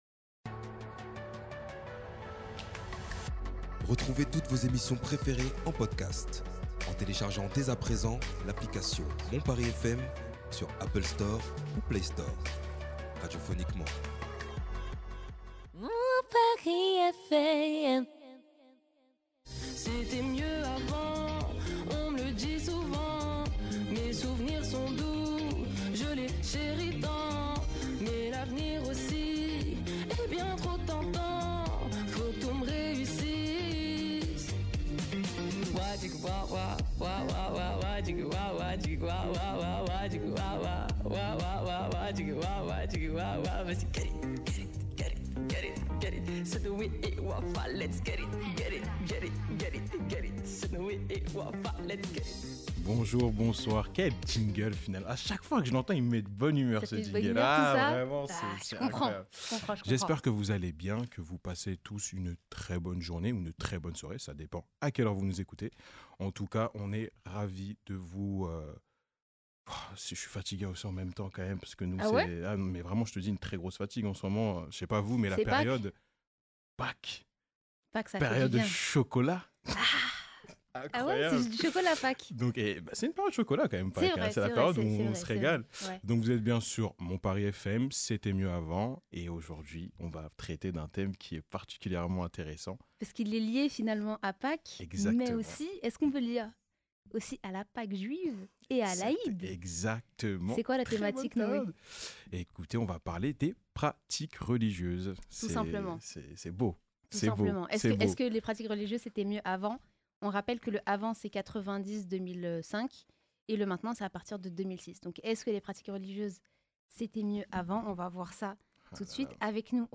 On prendra le temps de parler de nos expériences et nos connaissances personnelles vis à vis de nos religions respe